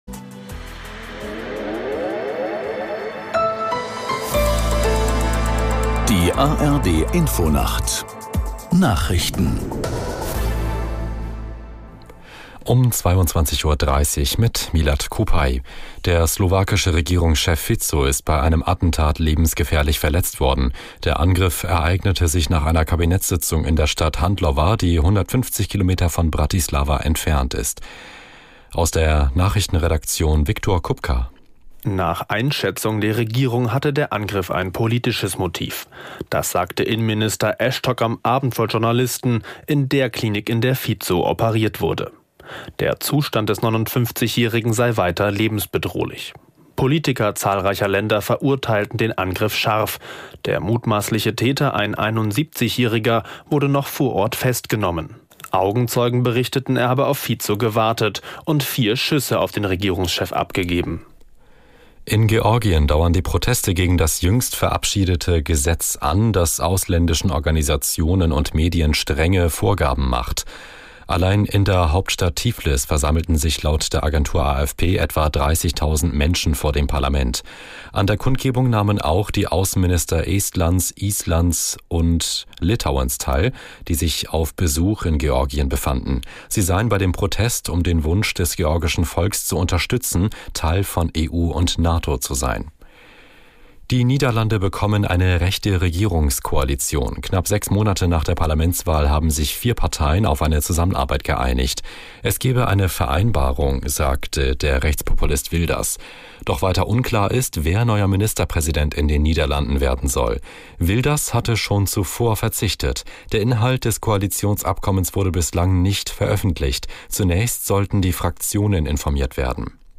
Nachrichten - 16.05.2024